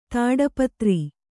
♪ tāḍapatri